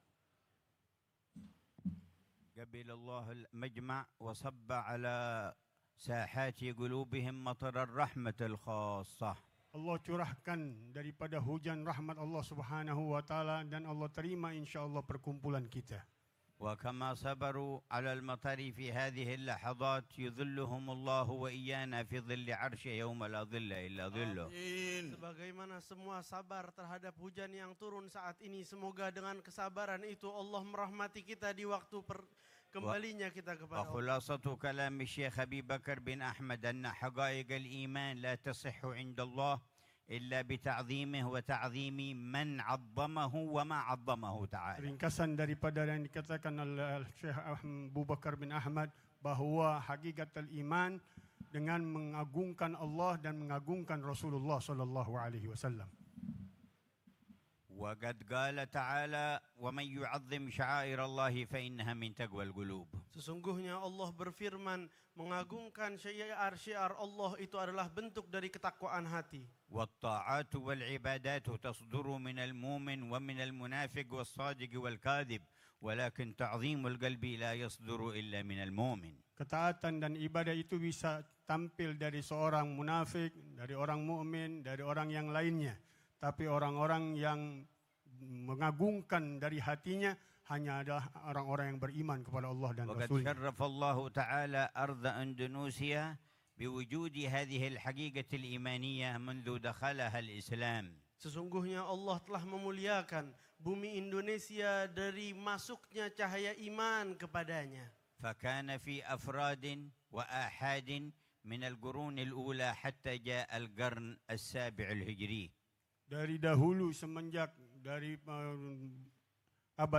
محاضرة في المجلس الكبير في ميدان موناس جاكرتا 1447 | Tausia Monas Jakarta
محاضرة العلامة الحبيب عمر بن محمد بن حفيظ في مجلس الوعظ والتذكير الكبير في ميدان موناس، في العاصمة الإندونيسية جاكرتا، ليلة السبت 26 ربيع الثاني 1447هـ